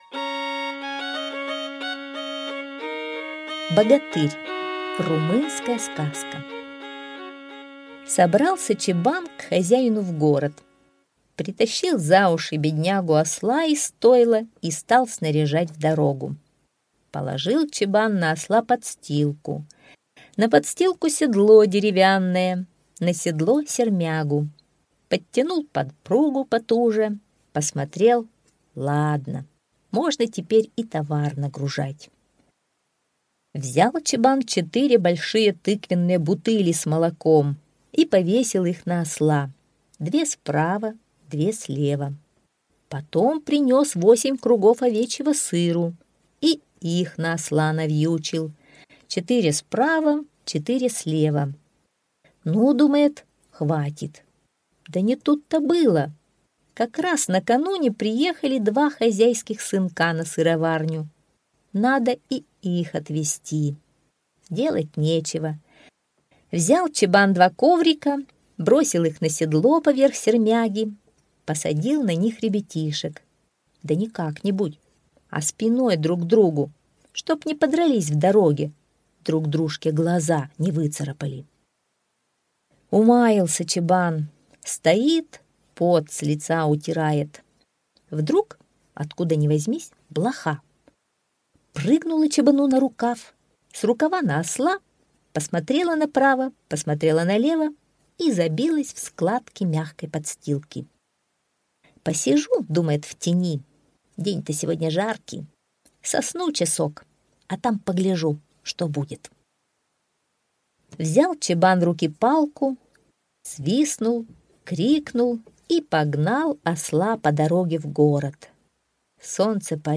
Богатырь – румынская аудиосказка